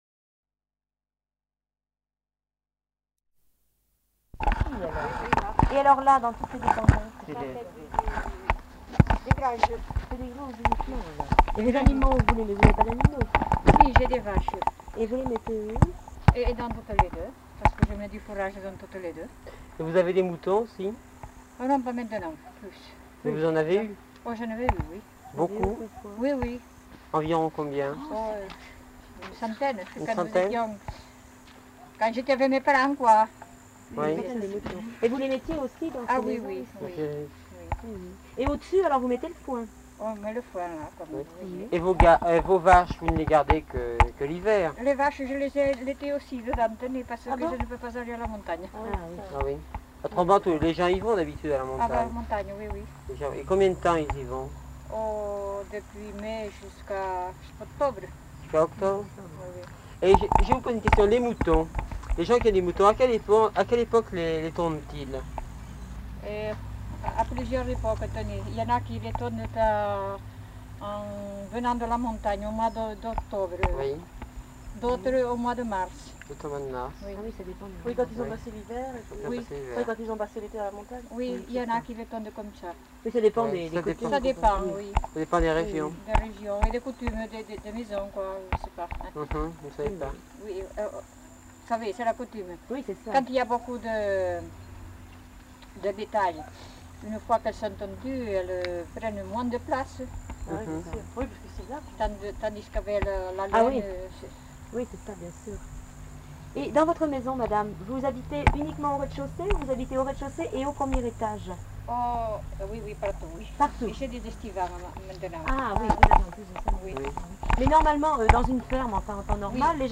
Aire culturelle : Bigorre
Genre : témoignage thématique
Notes consultables : L'informatrice n'est pas identifiée.